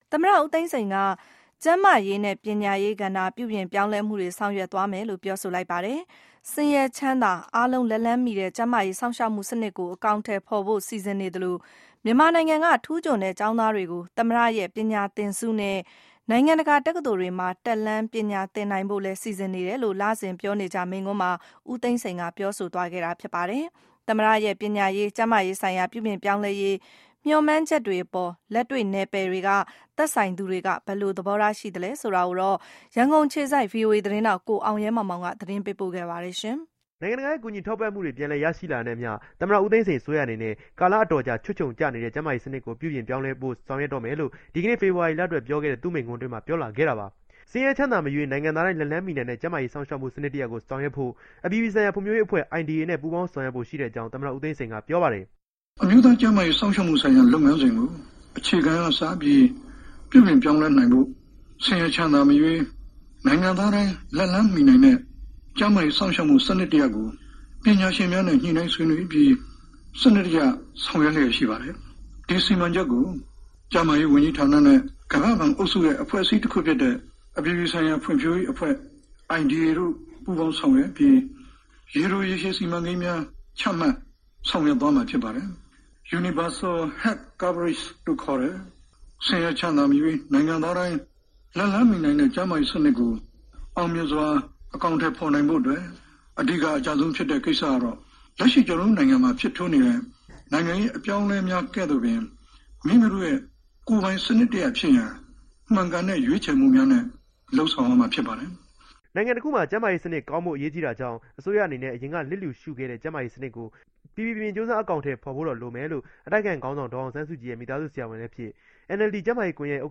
လစဉ်မိန့်ခွန်း